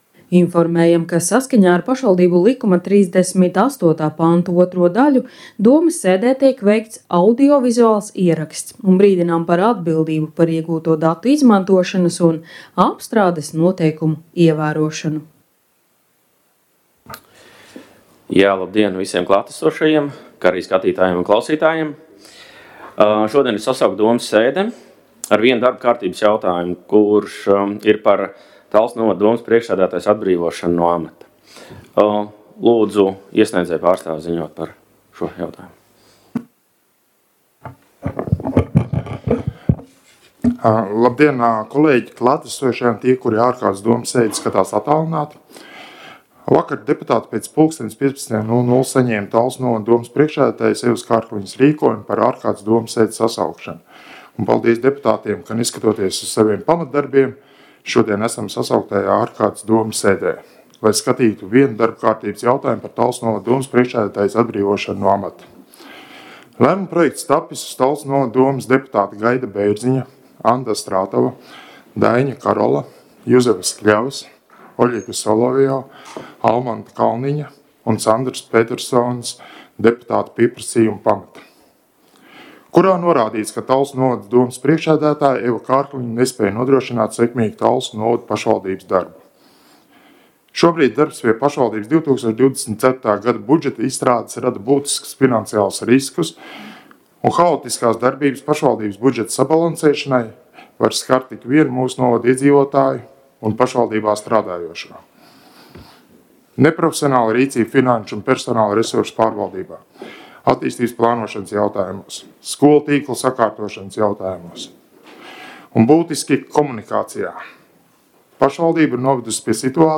Talsu novada domes ārkārtas sēde Nr. 2
Domes sēdes audio